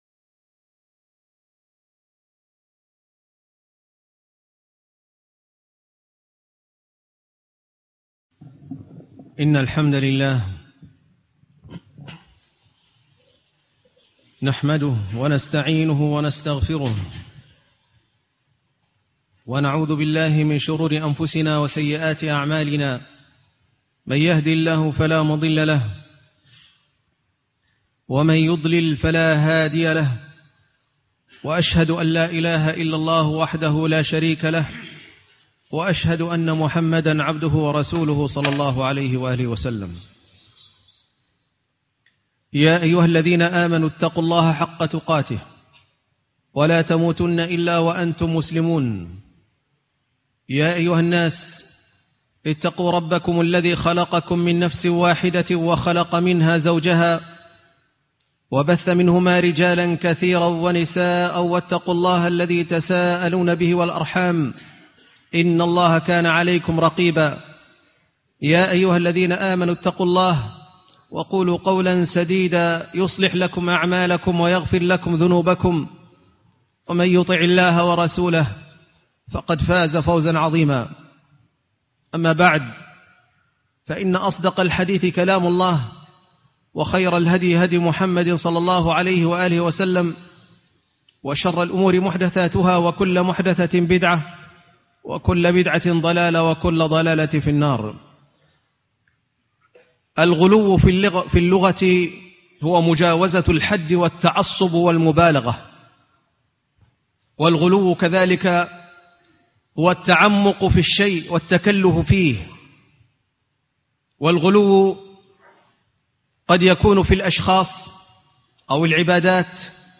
الجهلاء.. خوارج العصر - خطبة الجمعة